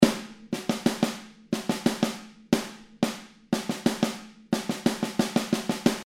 ostinato.mp3